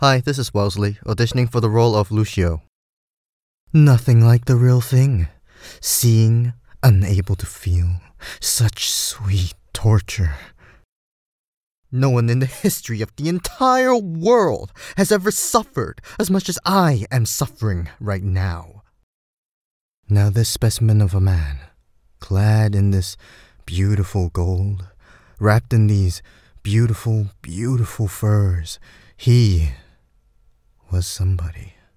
He exaggerates his suffering, loudly and dramatically, to wring sympathy out of anyone unfortunate enough to hear him.
Accent: The developers have confirmed that Count Lucio has a Jersey accent.
Voice: Medium or mid-high range, sneaky, sly, manipulative, slightly seductive, rough.
• male adult
• jersey